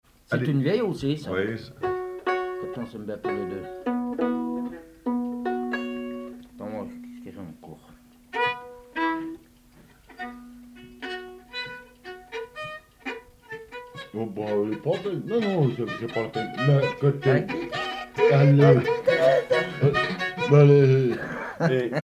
Bourrée
danse : bourree
circonstance : bal, dancerie
Pièce musicale inédite